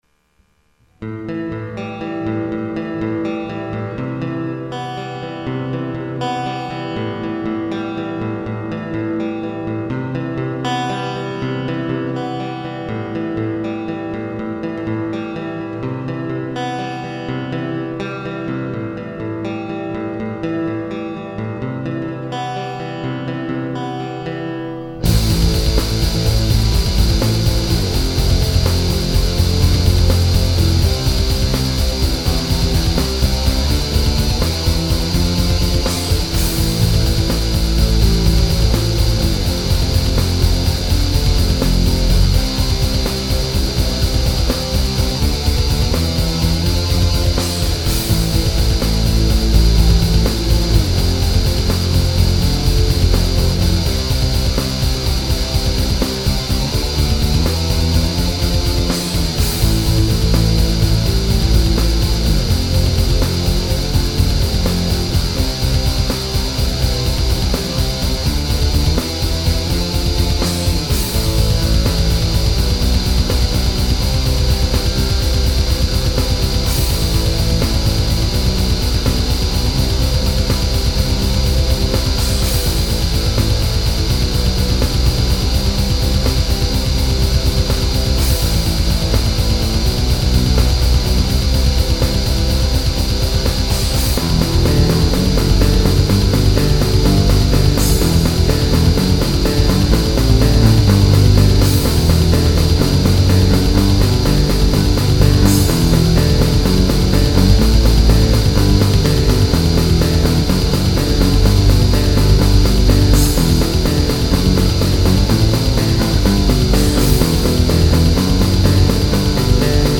*Genre: Atmospheric/Melancholical Folk/Black Metal